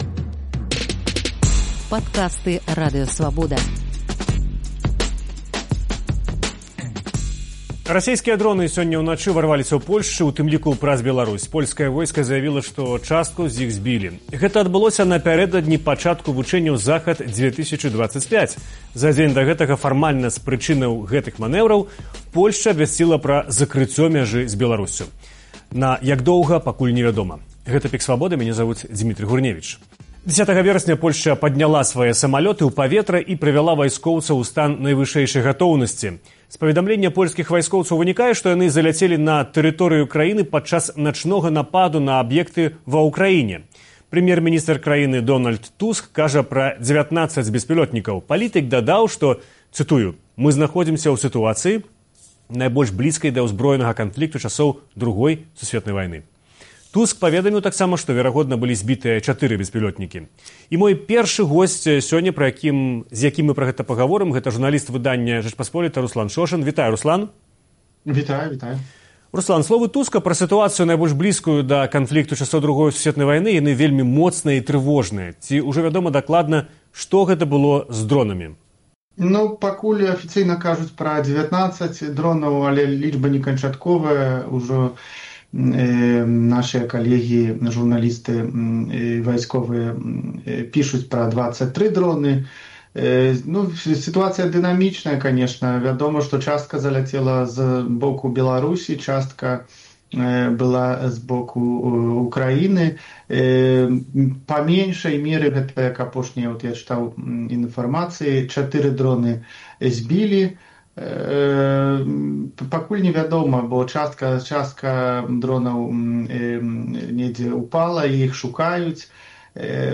Гэтыя падзеі ў эфіры «ПіКа Свабоды» абмяркоўваюць польскія журналісты